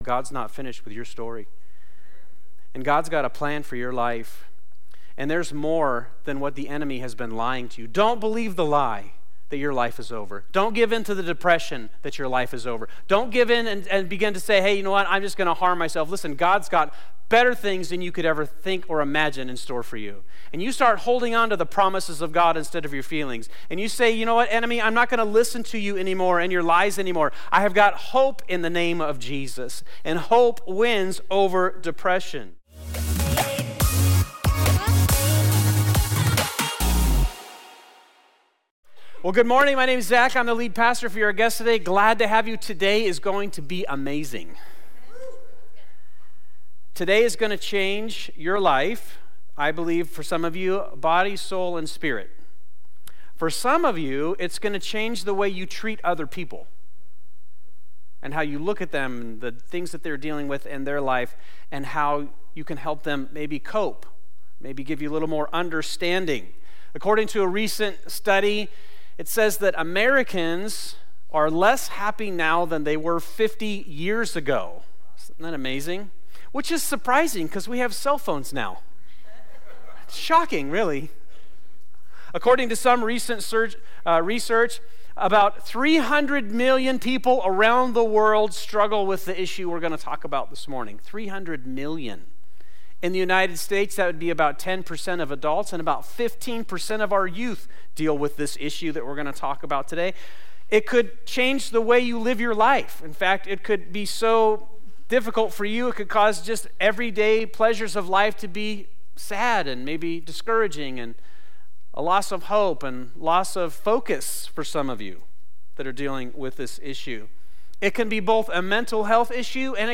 This is part 3 of our sermon series, "Hope Wins," at Fusion Christian Church.